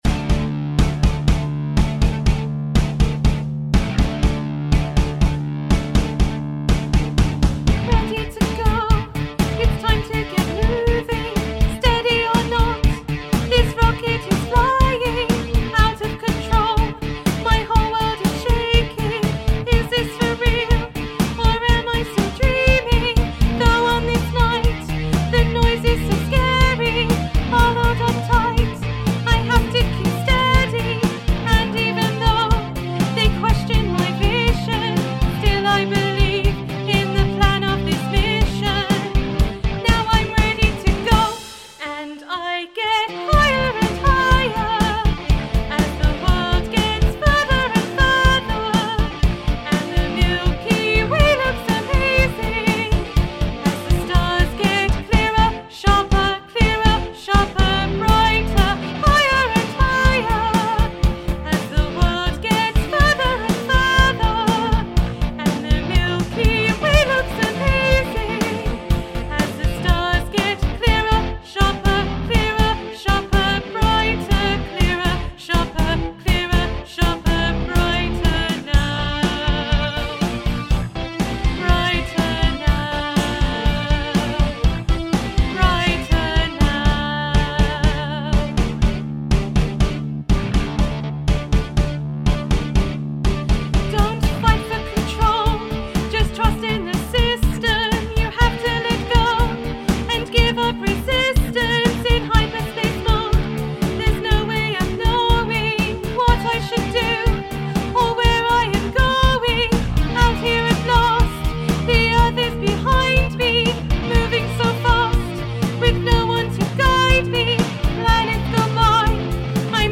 Higher and Higher - Vocals